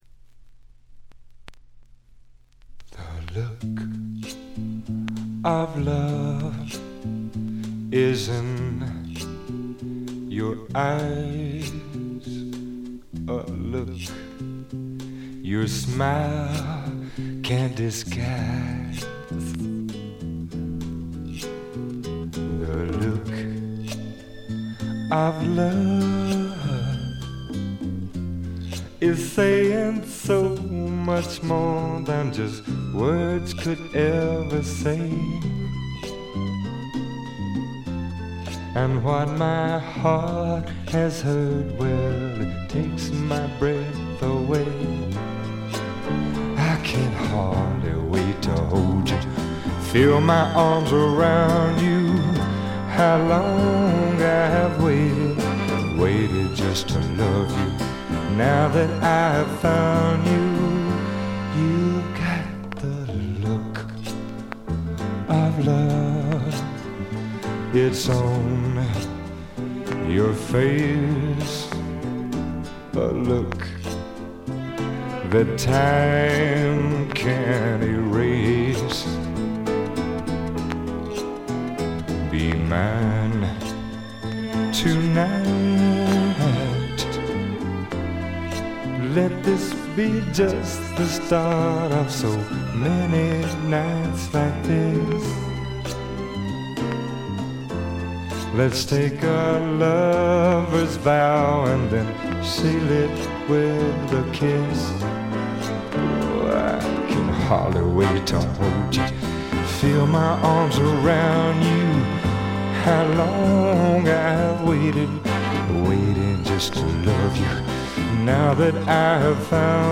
見た目よりよくなくて、バックグラウンドノイズ、チリプチ多め大きめ。B5中盤周回ぎみノイズ。
試聴曲は現品からの取り込み音源です。